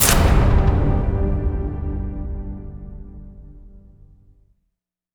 LC IMP SLAM 6C.WAV